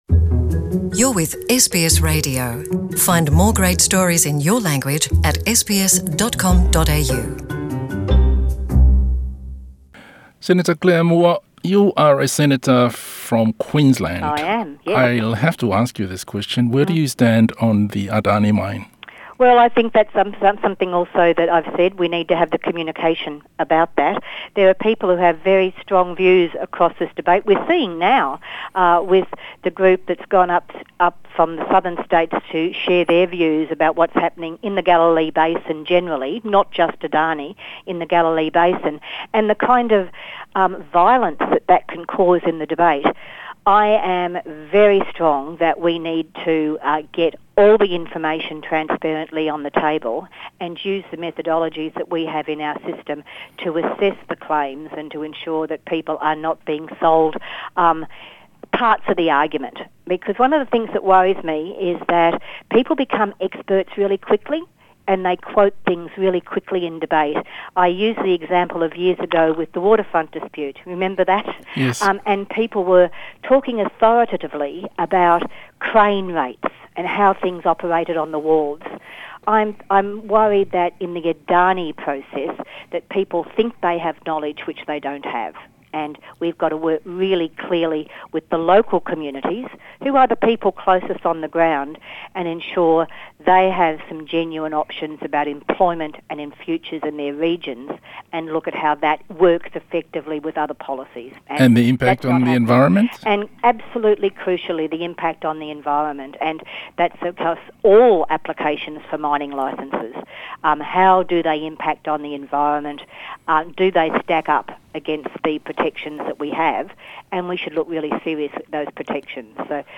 O se talanoaga lenei ma le sui o le ituagai le Labor mo mataupu i le Atina'e faavaomalo ma le Pasefika (International Development and the Pacific), Senator Claire Moore.